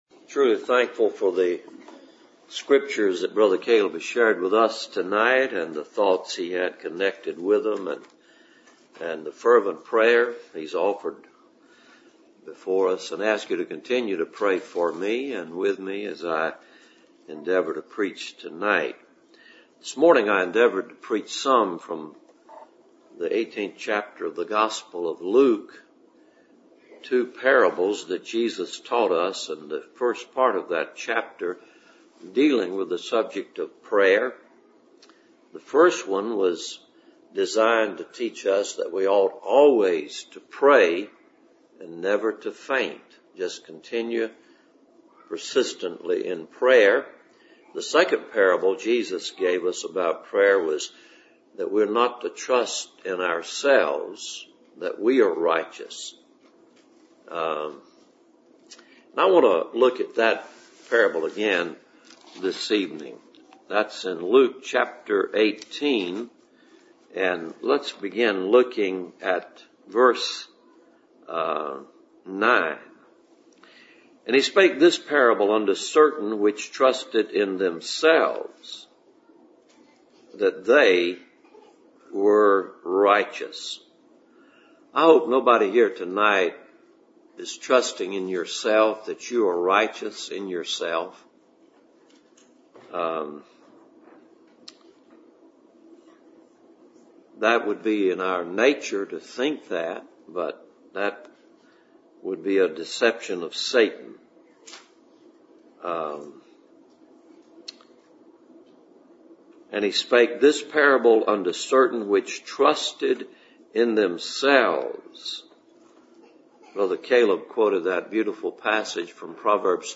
Cool Springs PBC Sunday Evening « Rejoice in the Lord